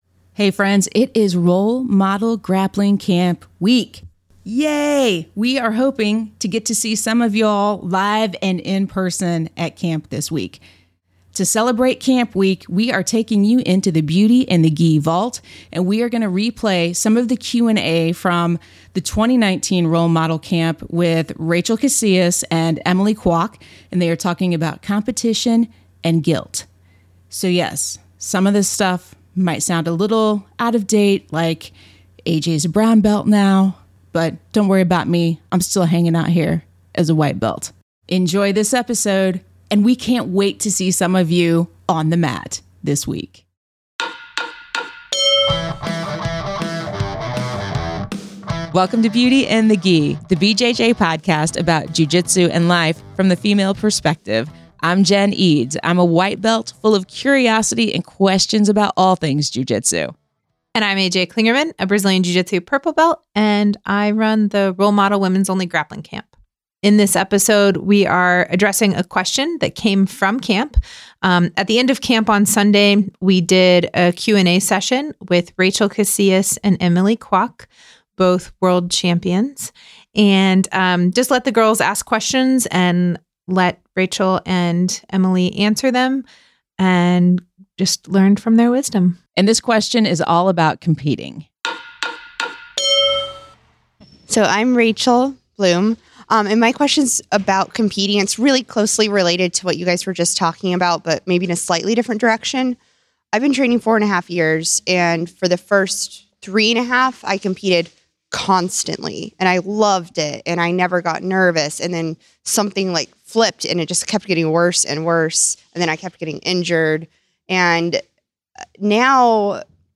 114: Competition and Guilt Questions from 2019 Roll Model Camp